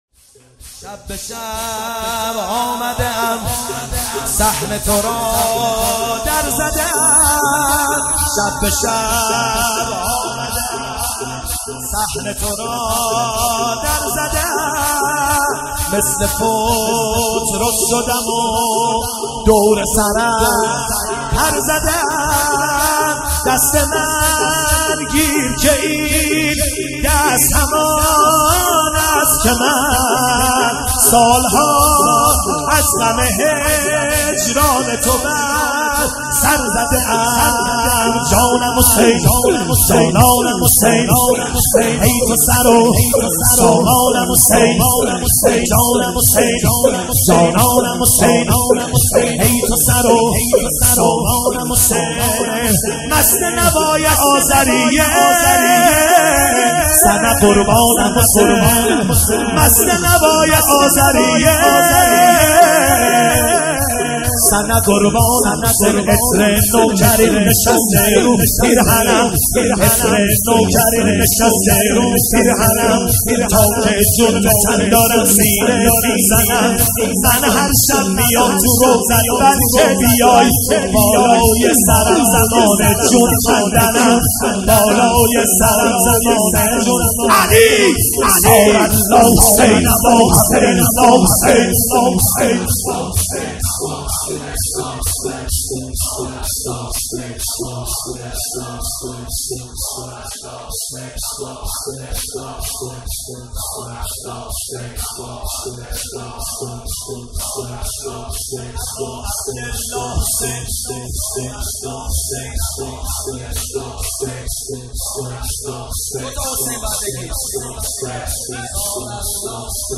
مداحی «شب به شب آمده ام»
با نوای دلنشین